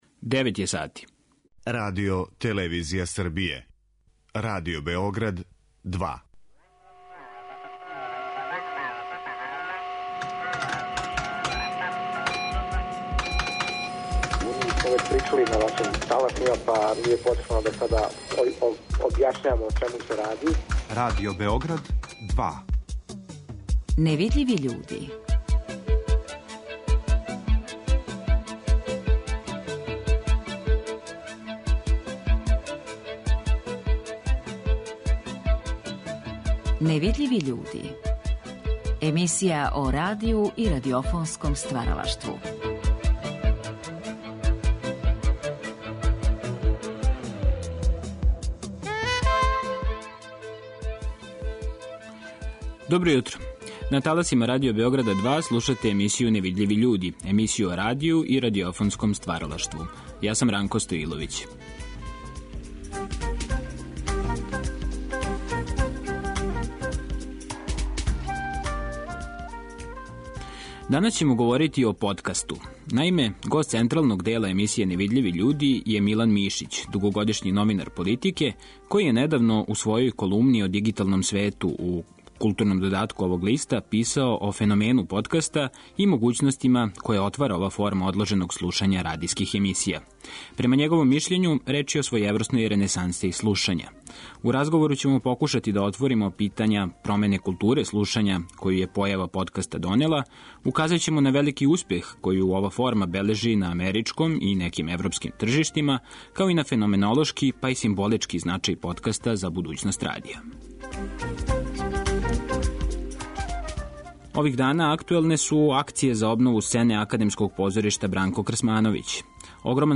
У "Невидљивим људима" ћемо се, захваљујући снимцима из ризнице нашег Тонског архива, подсетити гласа Николе Караклајића и његовог радијског стваралаштва.